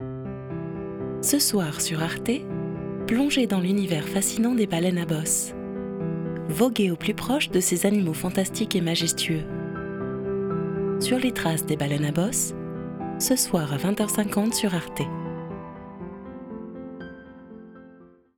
Enregistrements qualité studio
Voix d'annonce TV
Douce et captivante, parfaite pour e-learning et audiobook.
voix-douce-mysterieuse.wav